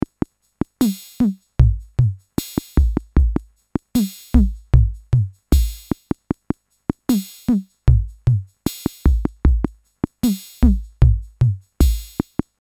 The library contains 16 drum loops from the built-in drum machine of the rare USSR electro-bayan «Orion» in collector’s quality 96 kHz, 32 bit float, with a total volume of 83 MB.